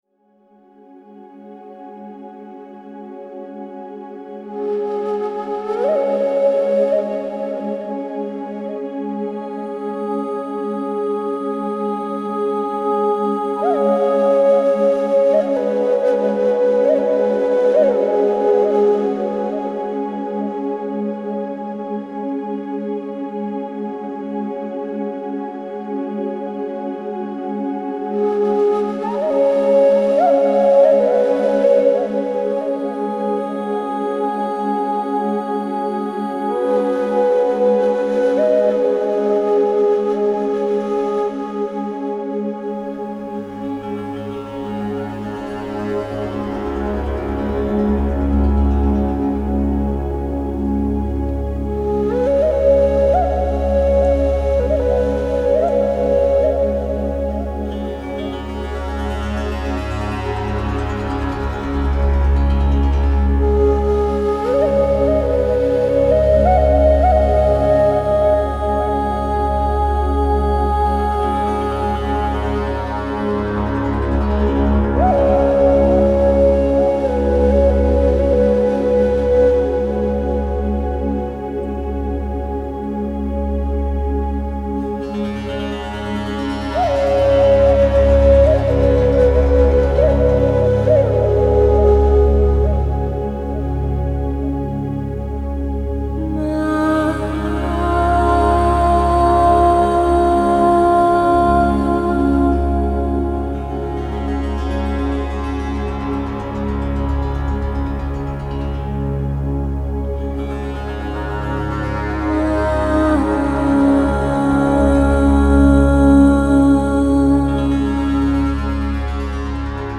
Also features sitar, tambura, Native American Indian flute